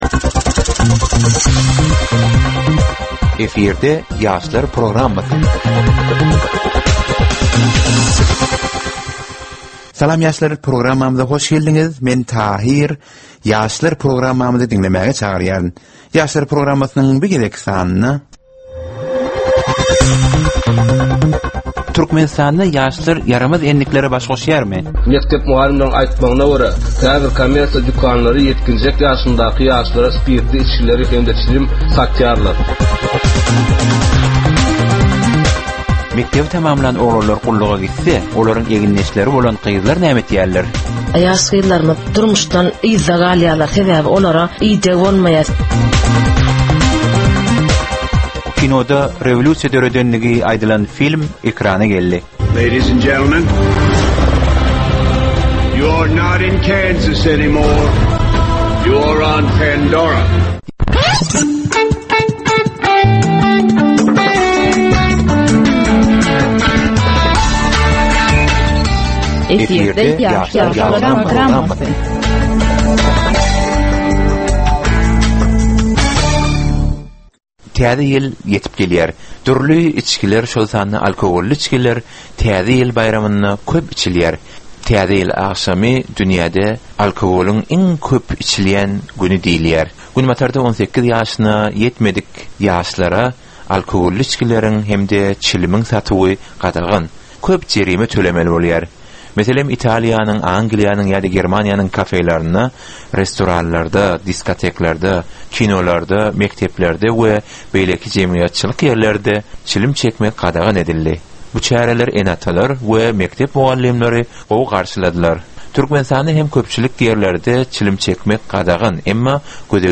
Gepleşigiň dowamynda aýdym-sazlar hem eşitdirilýär.